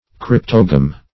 Cryptogam \Cryp"to*gam\ (kr?p"t?-g?m), n. [Cf. F. cryptogame.